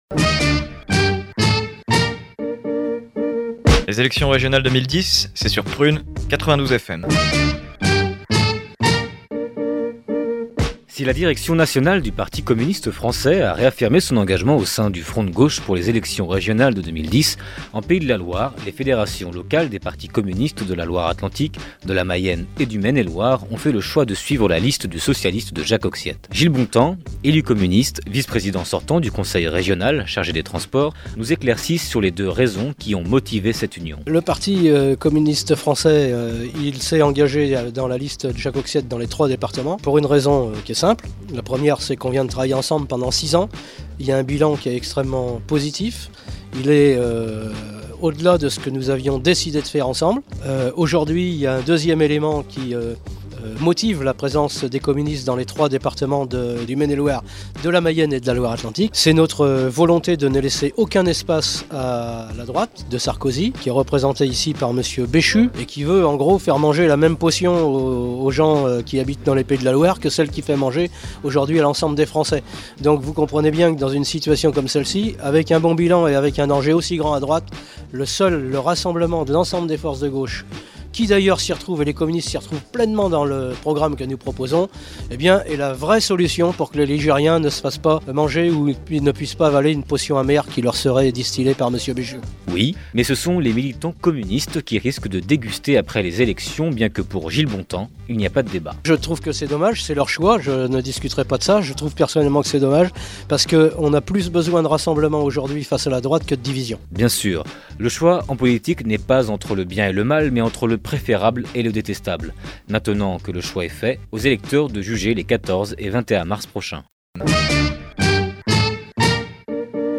Au micro de Prun’, le conseiller Régional nous donne la raison du ralliement du Parti Communiste Français dans la Région Pays-de-la-Loire à la Liste PS de Jacques Auxiette pour ces élections Régionales 2010.